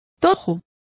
Also find out how tojo is pronounced correctly.